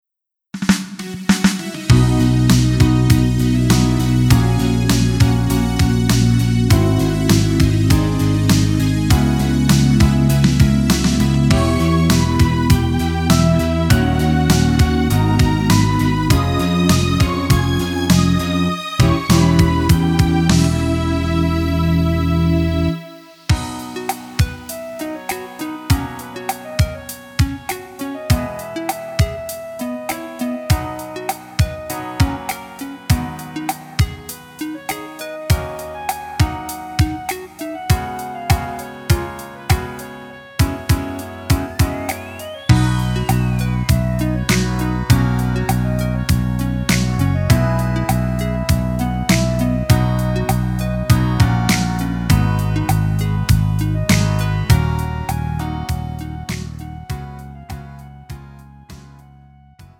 음정 원키
장르 구분 Pro MR